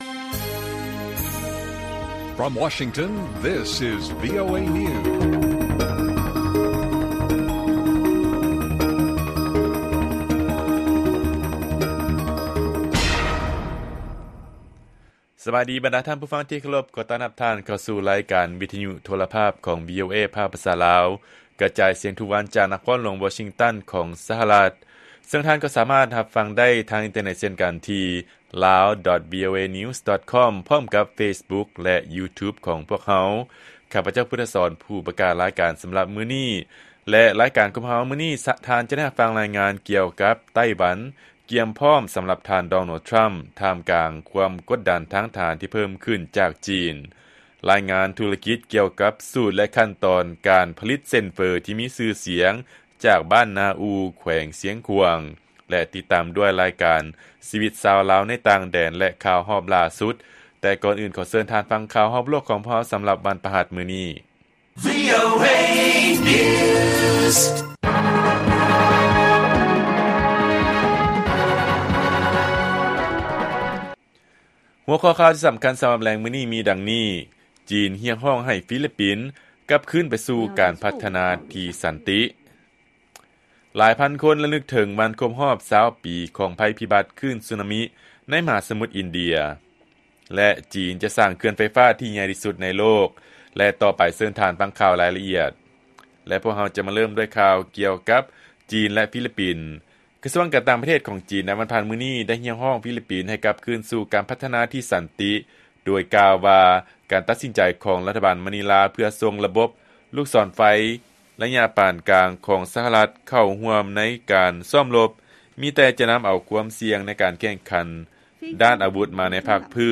ລາຍການກະຈາຍສຽງຂອງວີໂອເອ ລາວ ວັນທີ 26 ທັນວາ 2024